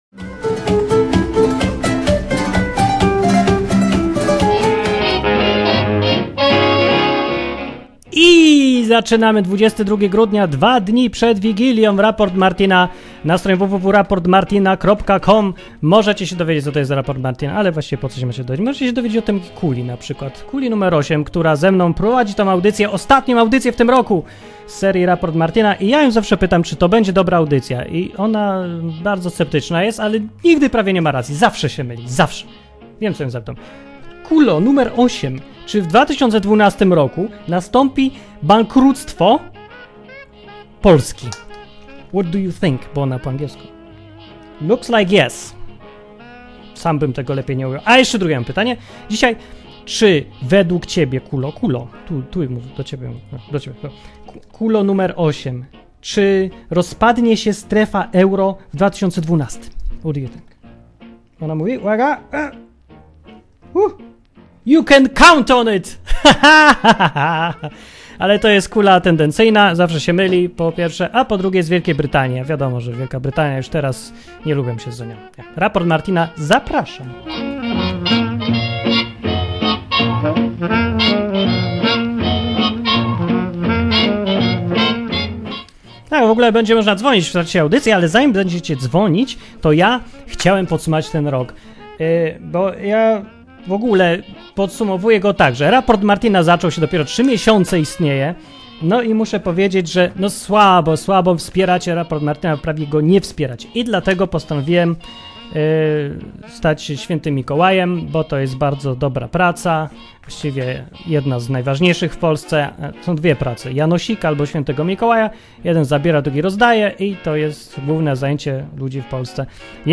Były informacje, komentarze, słuchacze, wszystko co najlepsze.